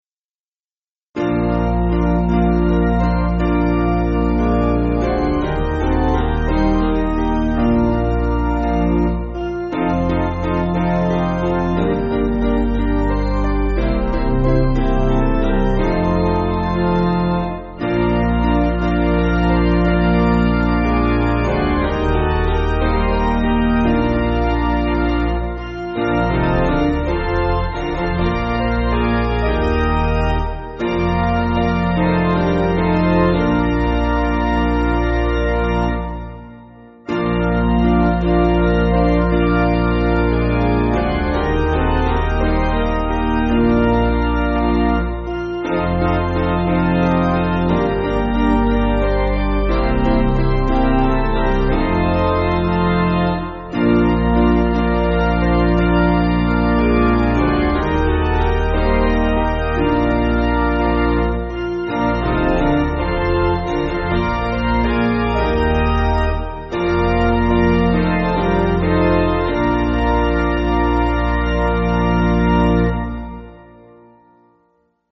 Basic Piano & Organ
(CM)   2/Bb